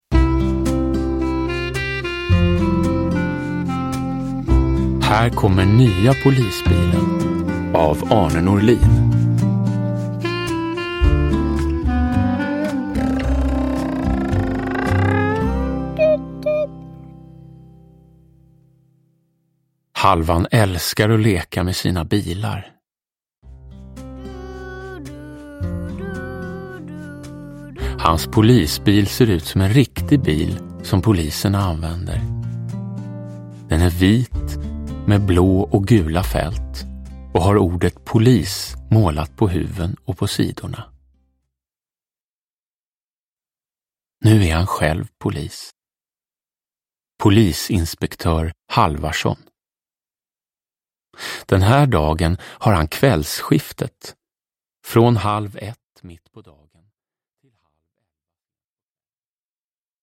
Uppläsare: Jonas Karlsson